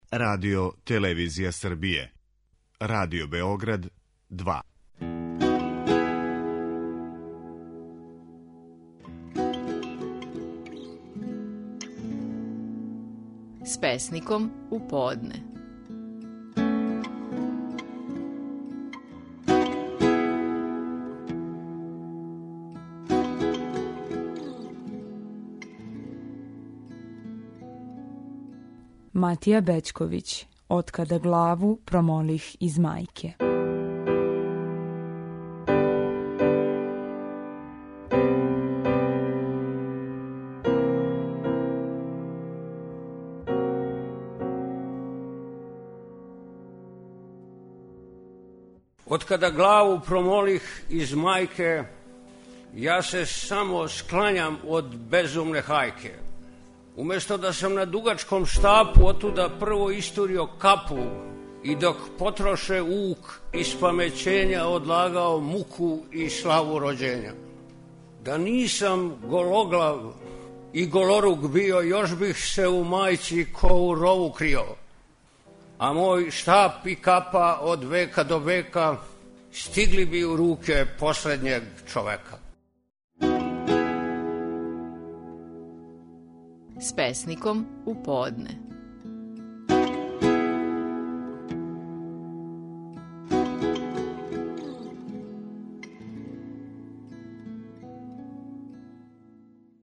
Стихови наших најпознатијих песника, у интерпретацији аутора.